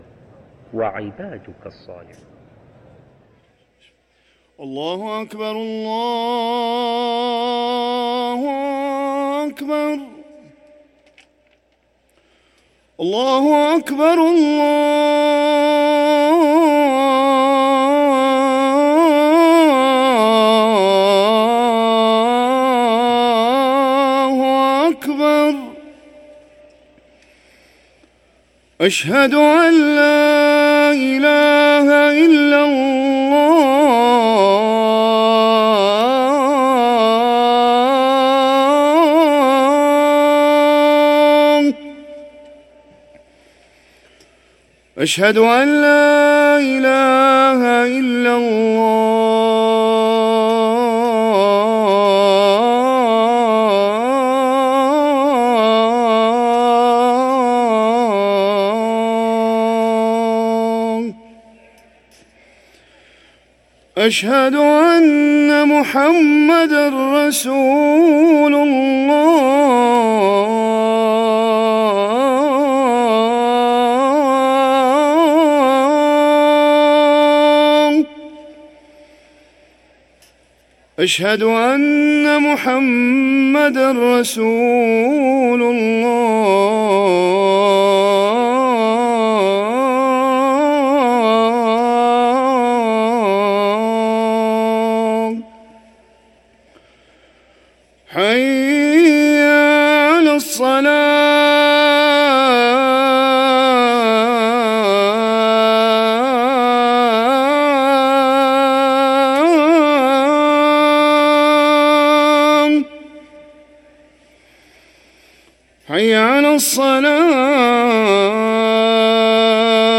أذان المغرب
ركن الأذان